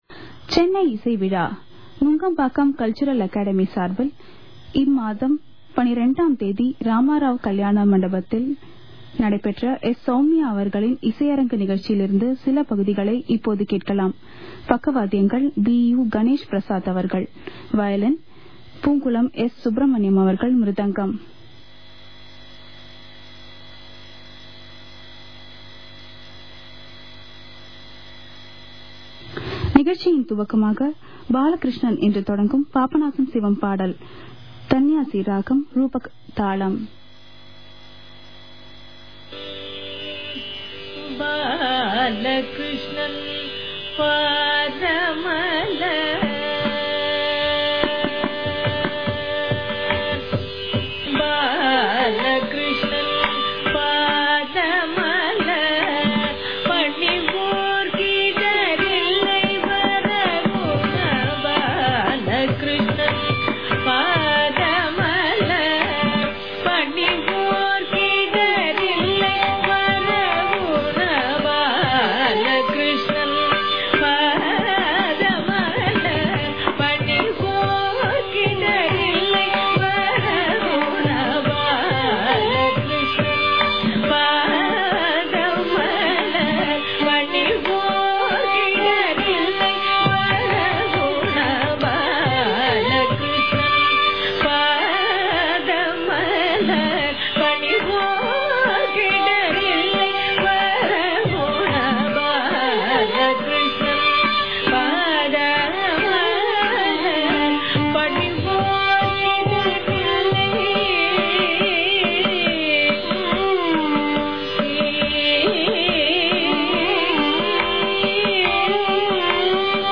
This radio recording
Violin
Mridangam